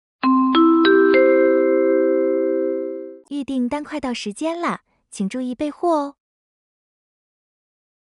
新提示音+语音 1-4.mp3